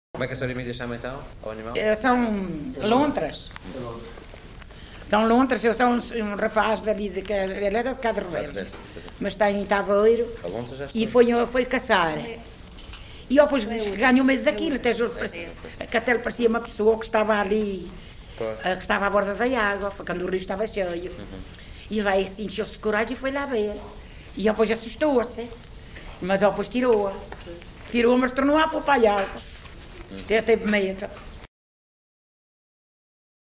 LocalidadeVila Pouca do Campo (Coimbra, Coimbra)